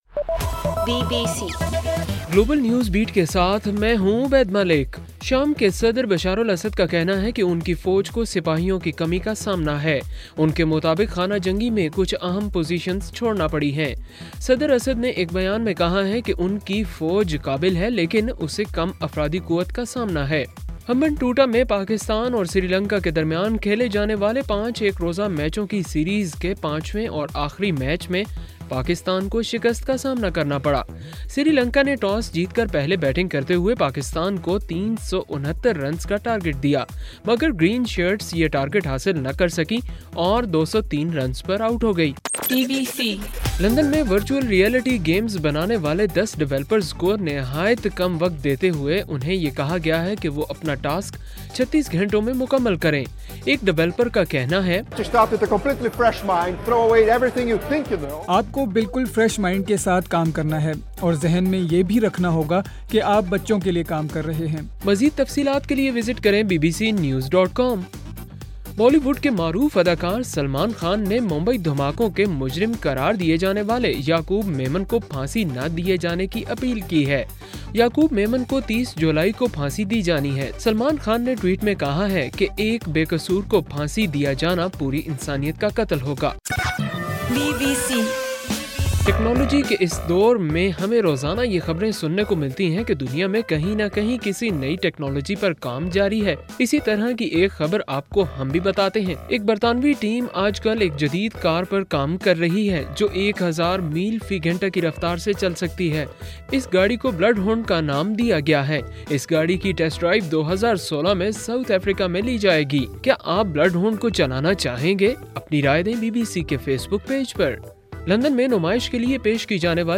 جولائی 27: صبح 1 بجے کا گلوبل نیوز بیٹ بُلیٹن